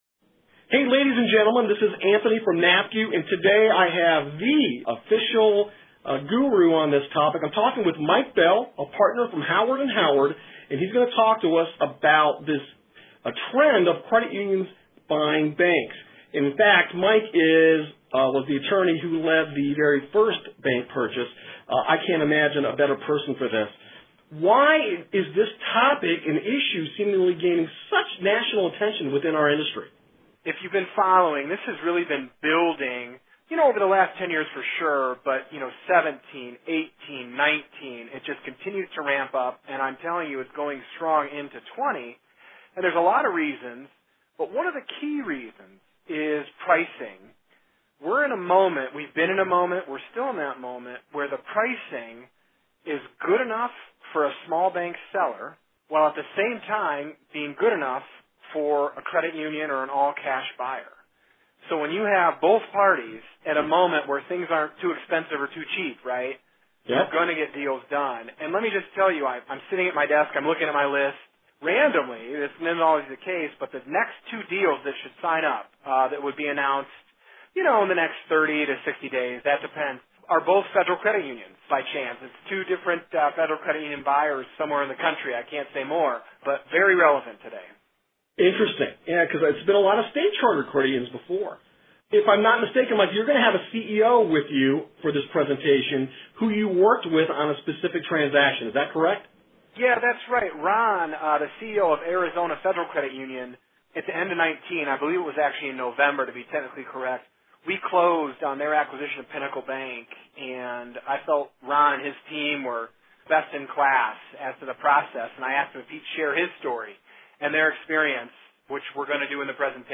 Interview transcript available below.